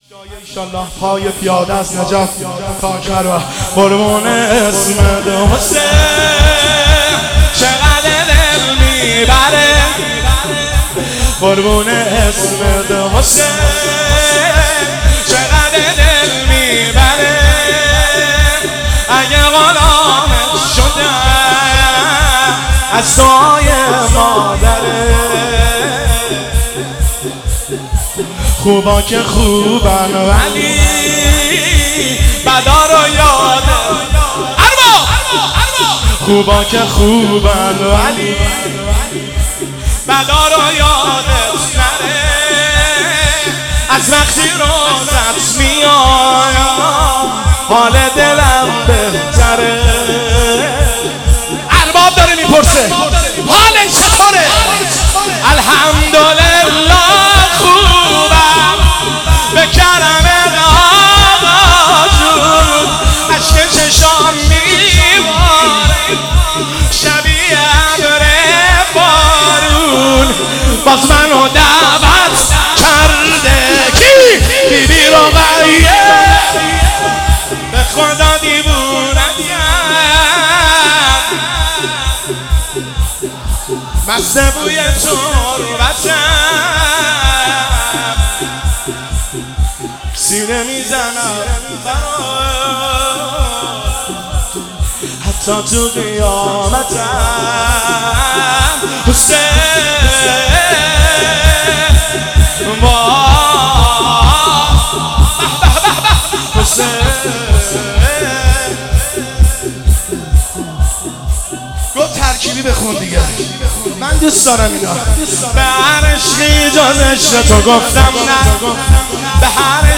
روضه هفتگی 17 اردیبهشت